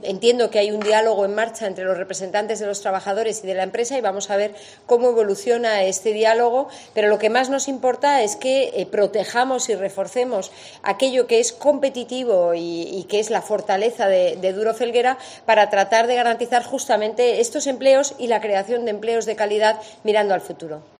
Así lo ha asegurado en rueda de prensa en Oviedo tras mantener una reunión con el presidente del Principado, Adrián Barbón, en encuentro "muy productivo" en el que se ha refrendado la "colaboración" y "confianza mutua" que existe.